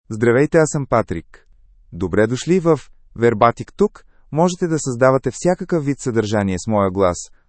MaleBulgarian (Bulgaria)
Patrick is a male AI voice for Bulgarian (Bulgaria).
Voice sample
Male